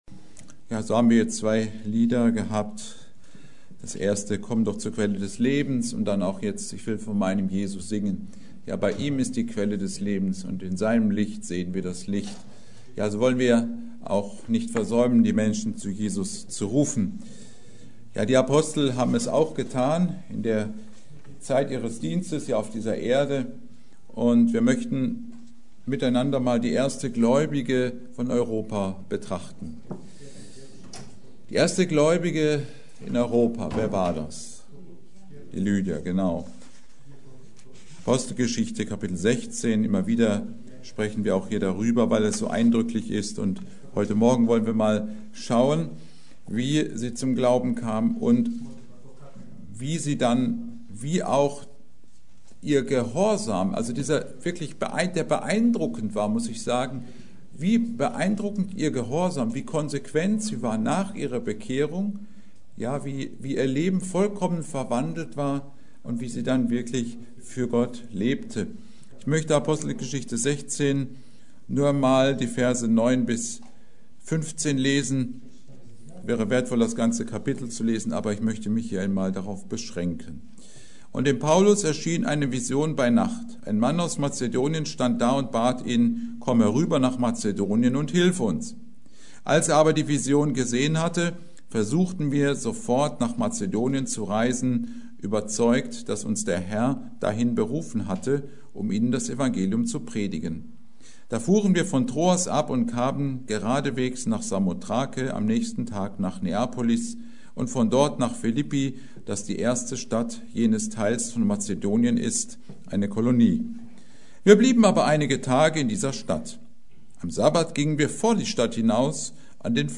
Predigt: Die erste Gläubige in Europa